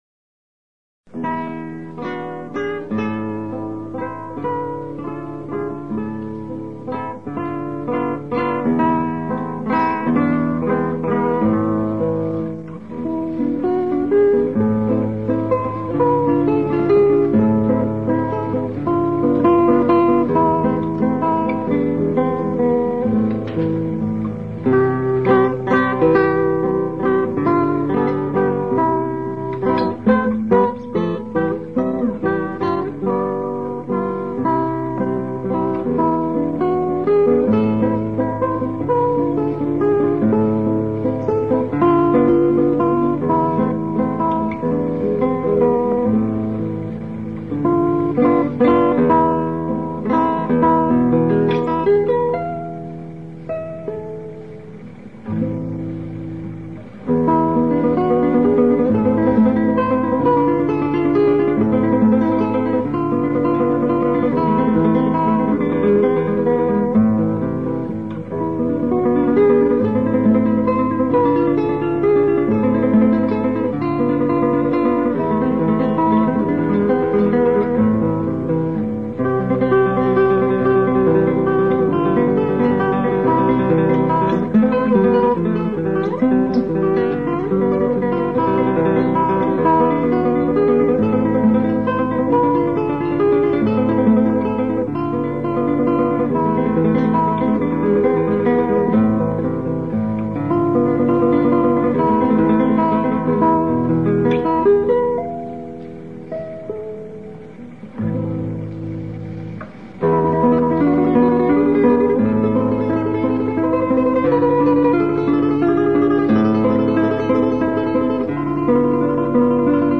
Kresge Little Theater MIT Cambridge, Massachusetts USA